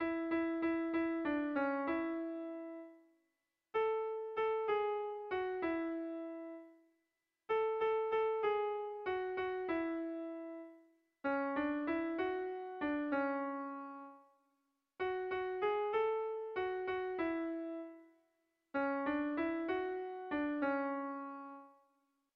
Kontakizunezkoa
Lauko txikia (hg) / Bi puntuko txikia (ip)
AB1B2